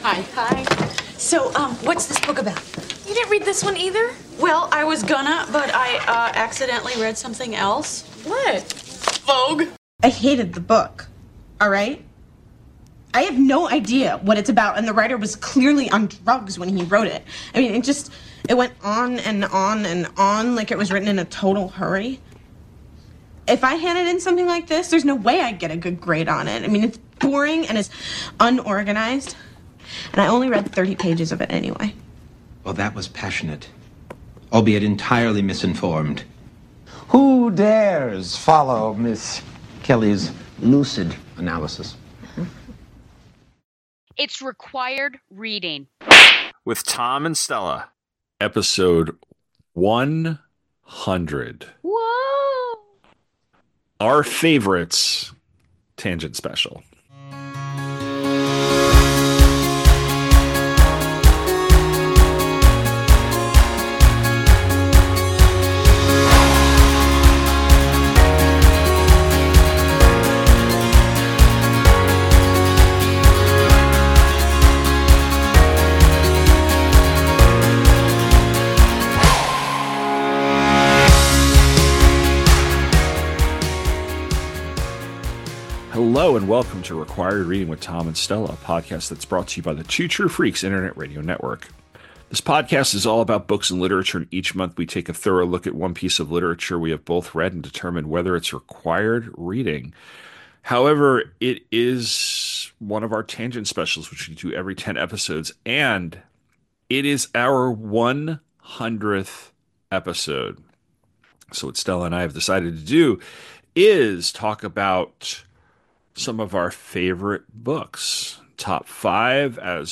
is two teachers talking about literature. Each episode, we will be taking a look at a single work, analyzing it, criticizing it and deciding if it’s required reading.